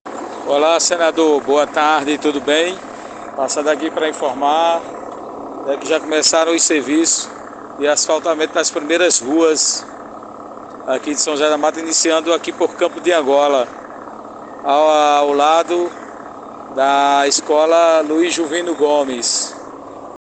“Passando para agradecer o início das pavimentações aqui no Distrito de São José da Mata, pelo Campo de Angola ao lado da escola Luís Jovino Gomes”, disse um dos moradores agradecidos ao senador e o prefeito.